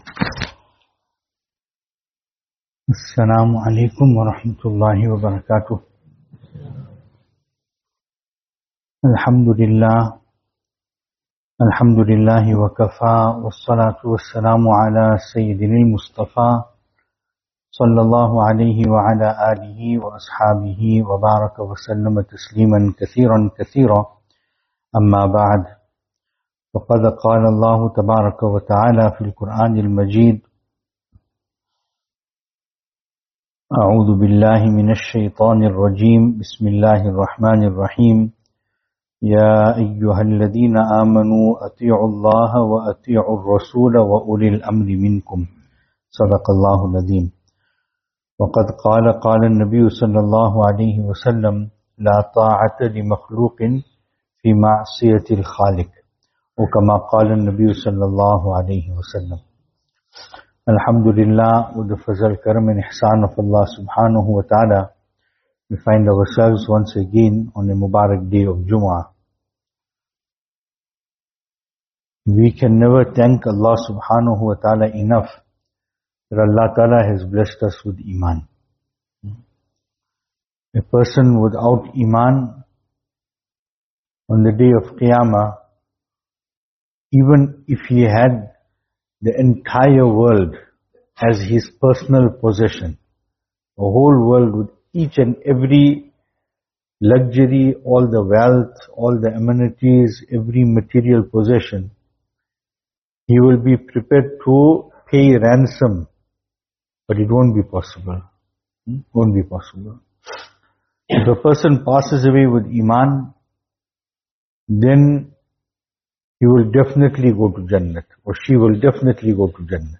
Venue: Albert Falls , Madressa Isha'atul Haq
Service Type: Jumu'ah